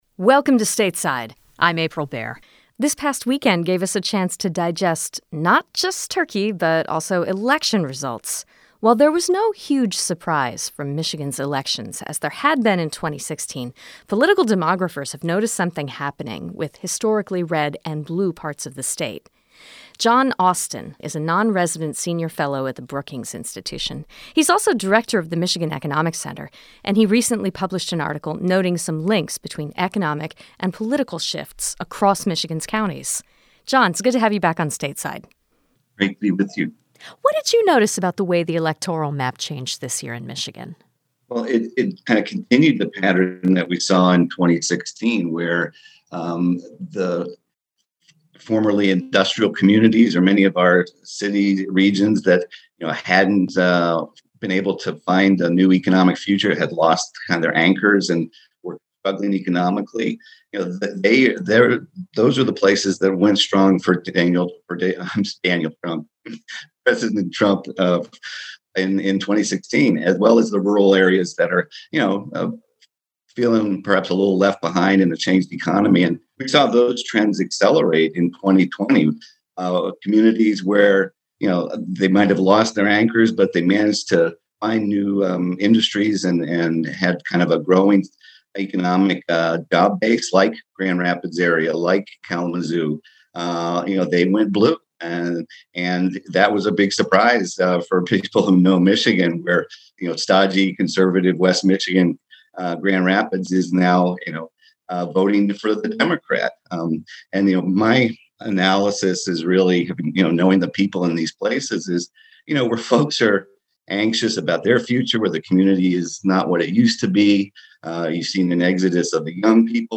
Today on Stateside, now that Michigan's ballots have been counted, political demographers are examining the state's 2020 election results. An expert at the Brookings Institute talked to us about how and where support for President Donald Trump formed roots in Michigan—and whether it's likely to continue after he leaves office.